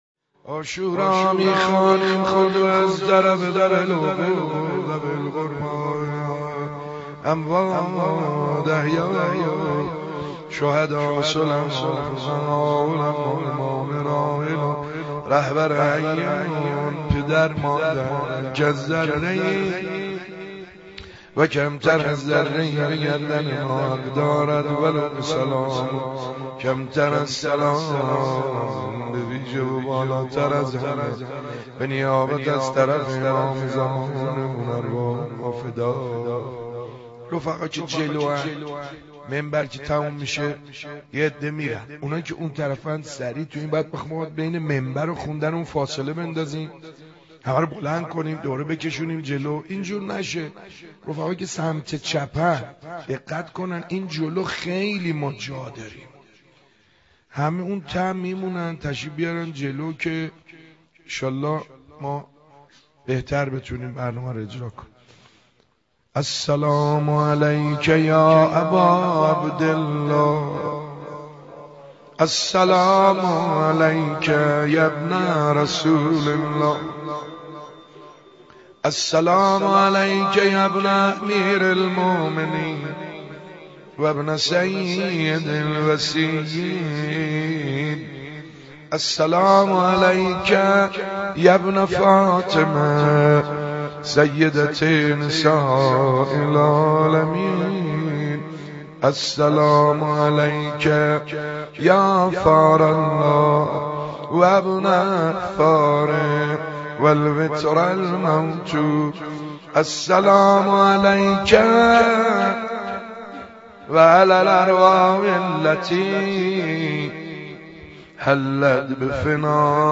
ای همه پیغمبران کنید از جا قیام مدح محمود کریمی